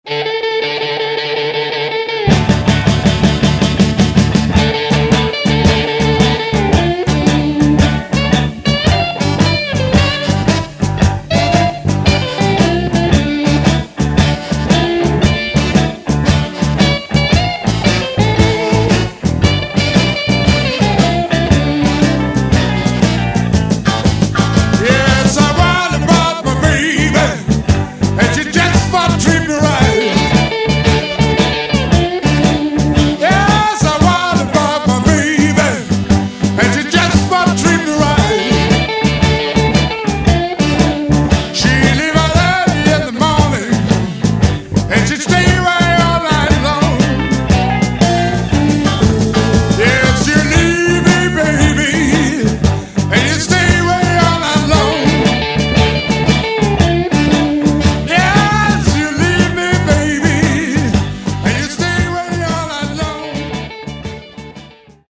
STUDIO 1983